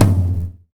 Royality free tom drum tuned to the F note. Loudest frequency: 1151Hz
• High Floor Tom Drum One Shot F Key 14.wav
high-floor-tom-drum-one-shot-f-key-14-jrb.wav